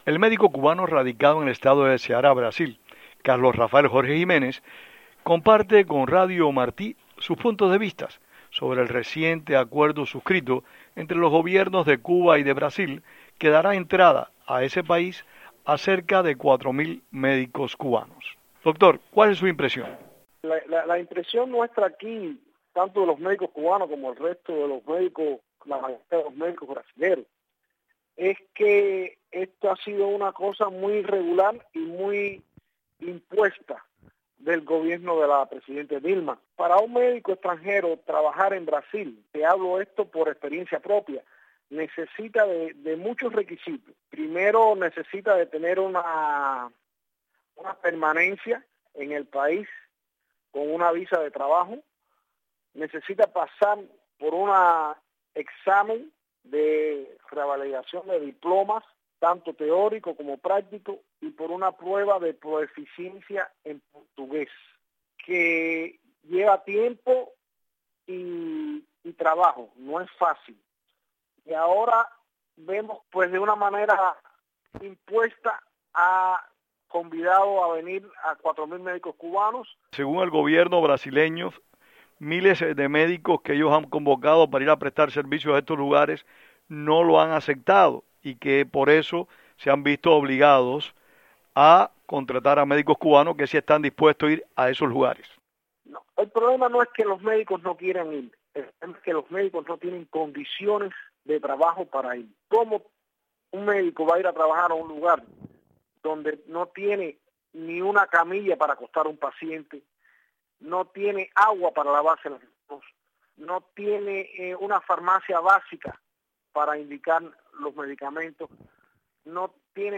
Médico cubano que labora en Brasil habla sobre la contratación de cuatro mil galenos cubanos para prestar sus servicios profesionales en ese país.